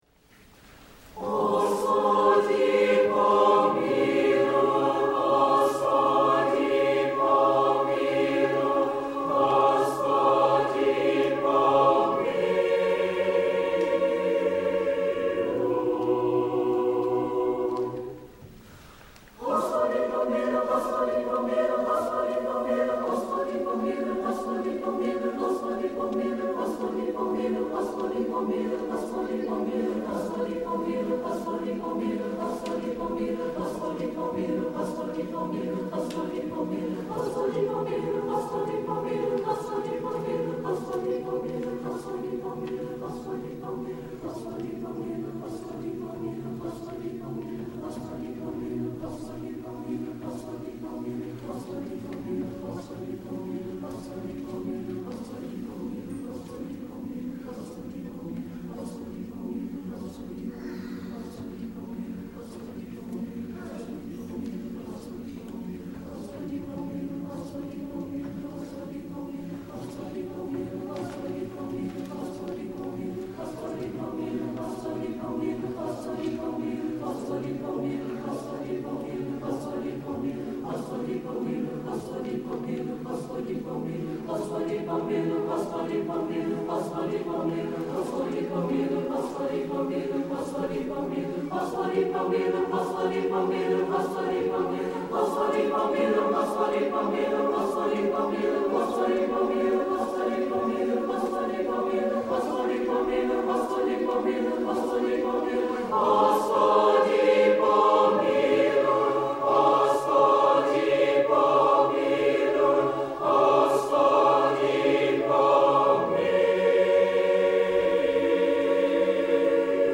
Voicing: TTB/TBB/TTBB (TTBBBB)